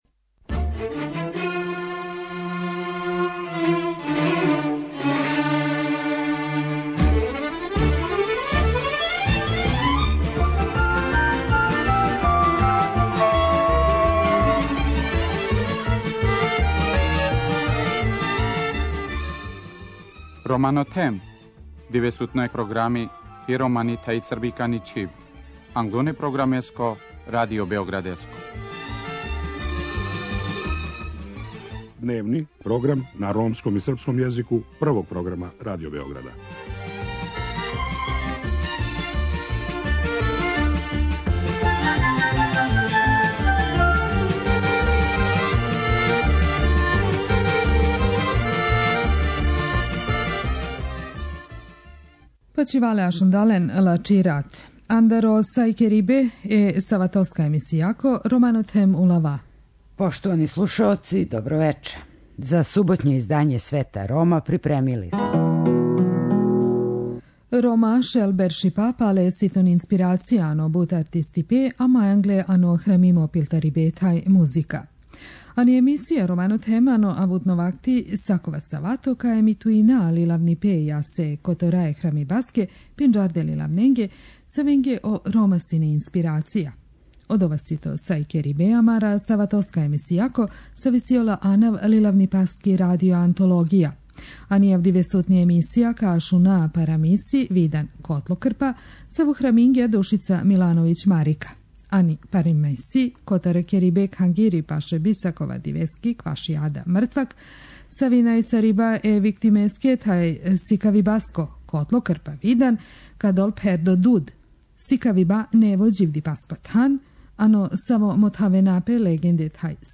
У емисији Свет Рома, сваке суботе емитујемо књижевно дело или одломке разних књижевника којима су Роми били инспирација.